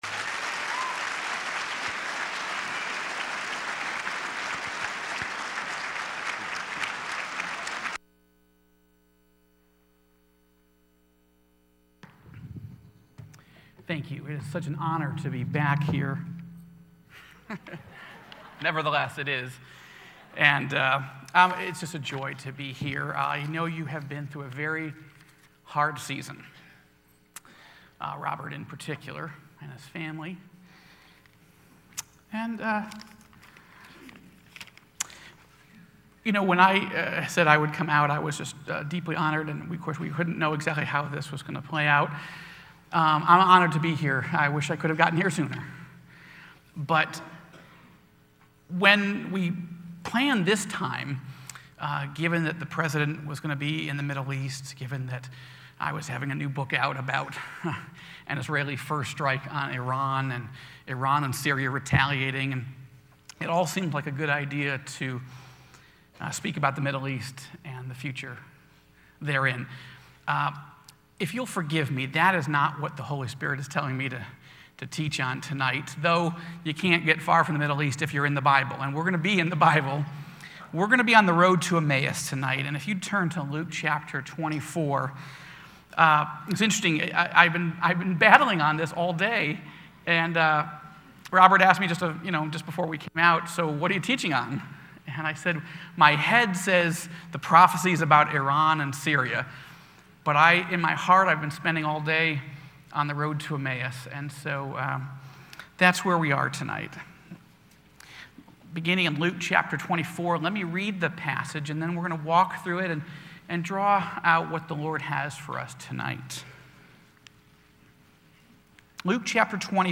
Joel Rosenberg: The Emmaus Road - Luke 24:13-31 Luke 24:13-31 · Mar 27, 2013 · Joel Rosenberg Listen to guest speaker Joel Rosenberg giving a message titled "The Emmaus Road" today starting in The Book of Luke Chapter 24, verse 13.